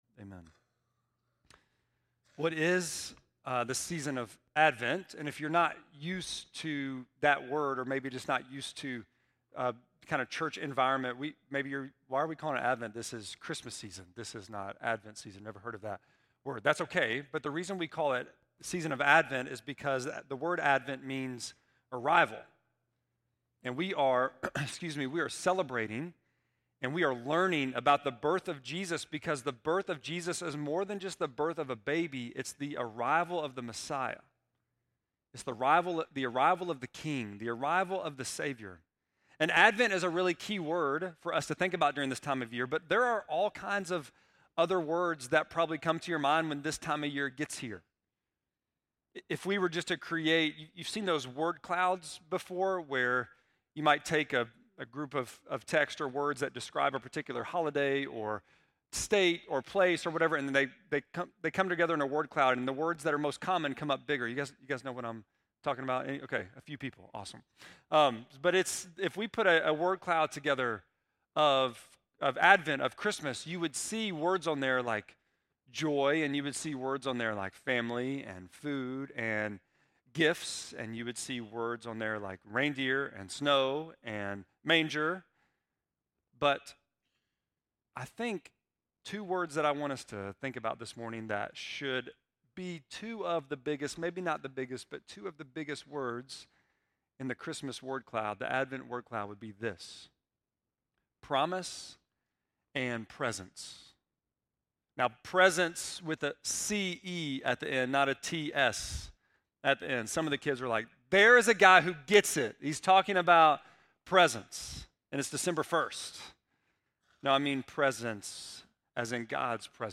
12.1-sermon.mp3